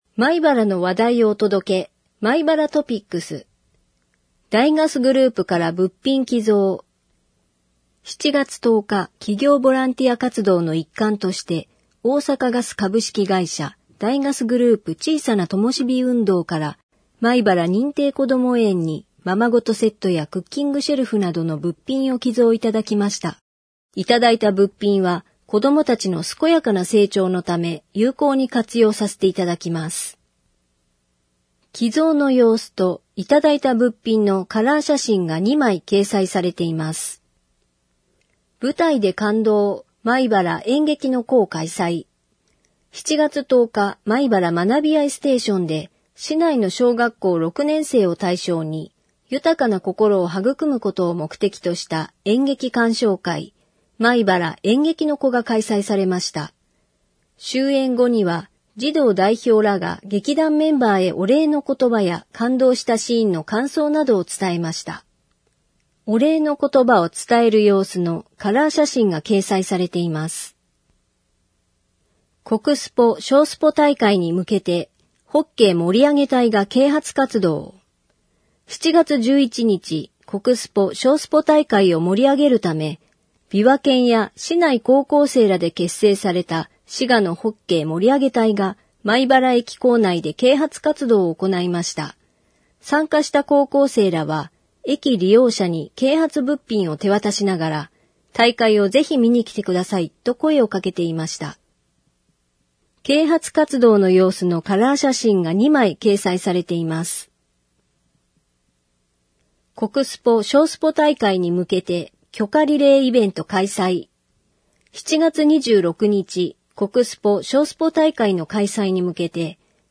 障がい者用に広報まいばらを音訳した音声データを掲載しています。
音声データは音訳グループのみなさんにご協力いただき作成しています。